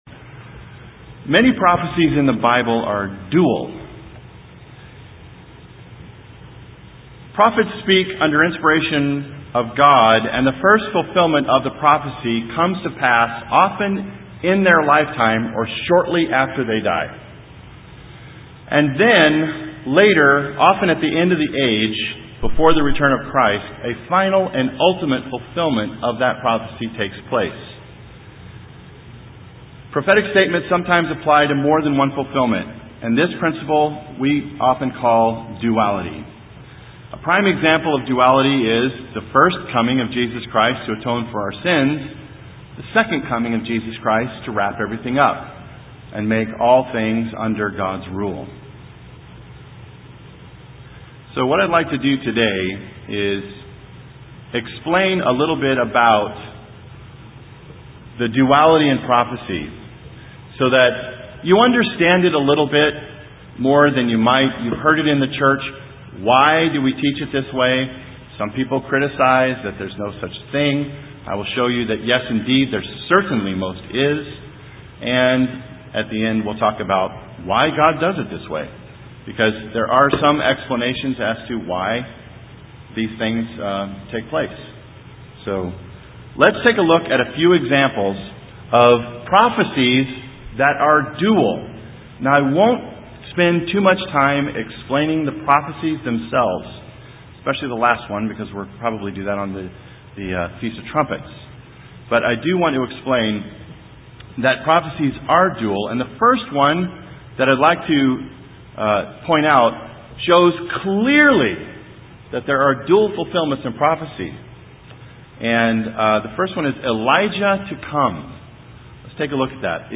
Prophetic statements about end time events are often dual in meaning. This sermon explains duality in prophecy.